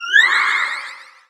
Cri de Cupcanaille dans Pokémon X et Y.